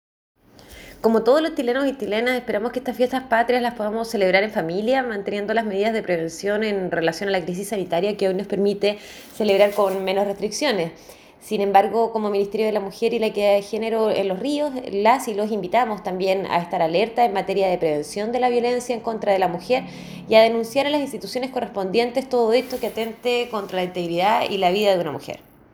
CUÑA-1-SEREMI-MMYEG-LOS-RIOS-KARLA-GUBERNATIS.mp3